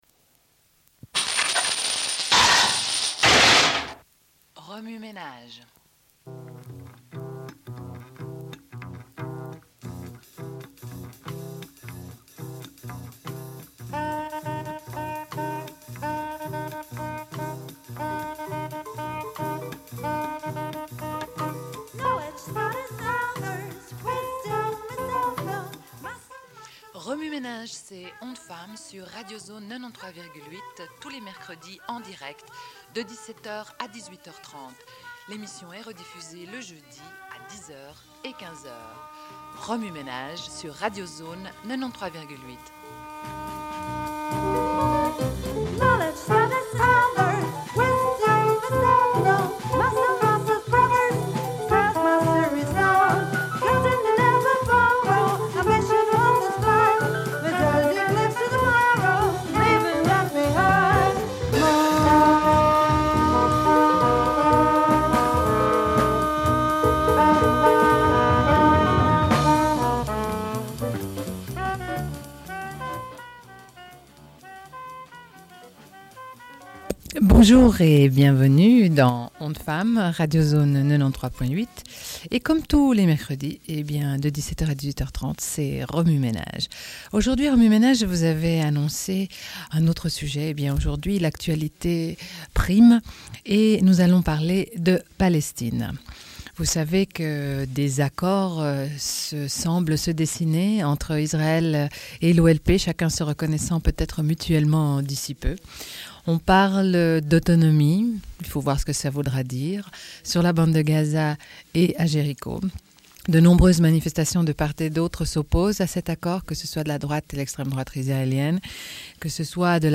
Une cassette audio, face A31:17